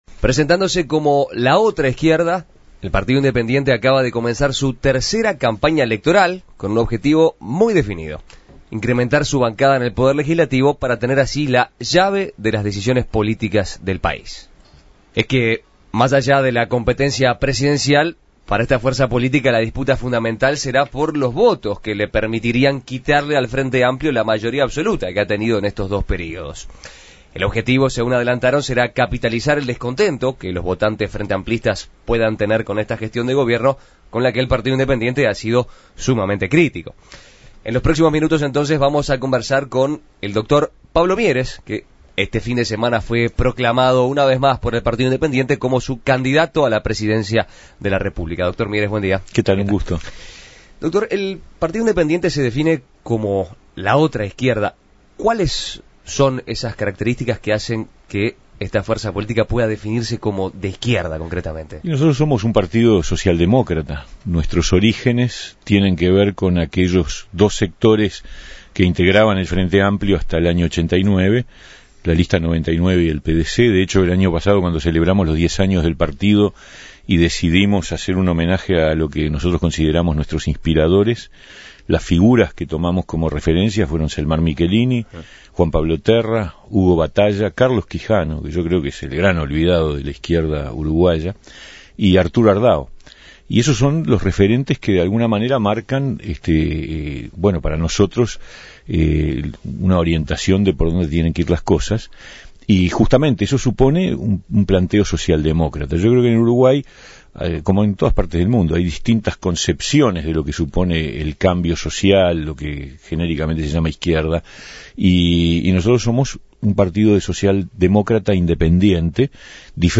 El Partido Independiente (PI) lanzó su campaña de cara a las elecciones del año próximo postulándose como "la otra izquierda". Su cometido principal será incrementar su bancada y terminar con la mayoría parlamentaria del Frente Amplio. Para conocer más sobre esta candidatura, En Perspectiva entrevistó a Pablo Mieres, candidato a la Presidencia por el PI, quien recalcó la idea de que el debate de la mayoría parlamentaria depende del crecimiento del PI, y considera que le haría bien al país que el FA no vuelva a tener esa mayoría.